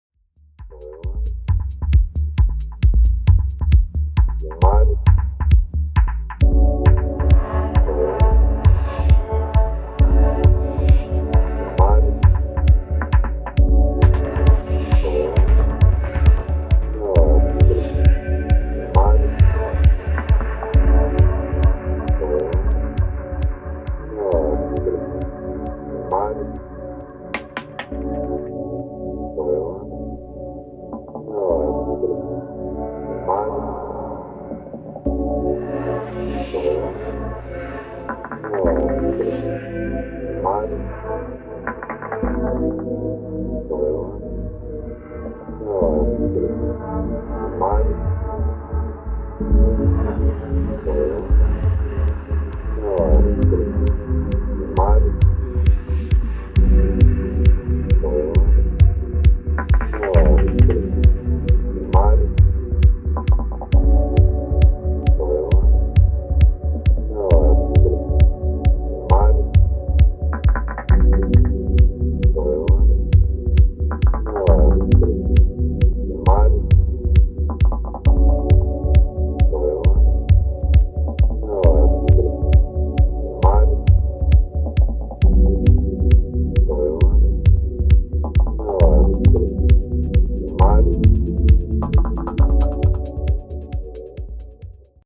Electro Electronix Indie Outernational